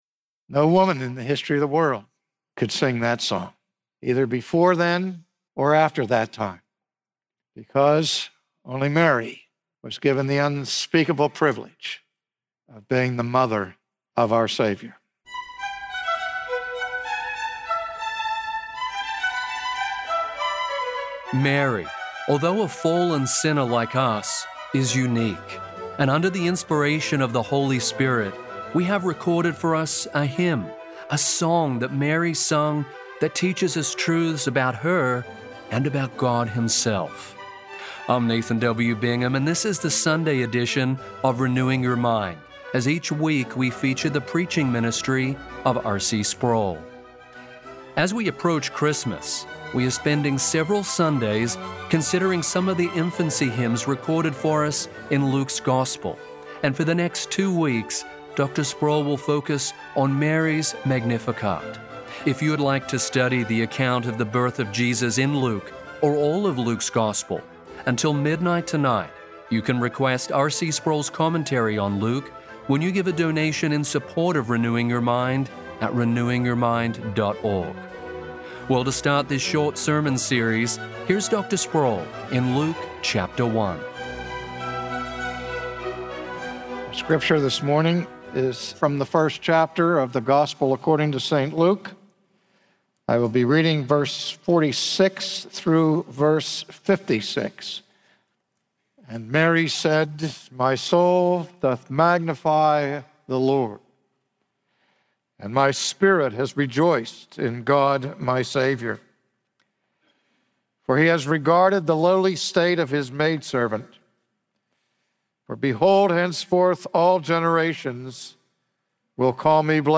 Pregnant with the long-promised Redeemer, Mary sang a song of adoration to the God who cares for the lowest of His servants. From his sermon series in the gospel of Luke